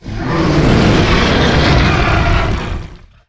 hive_death.wav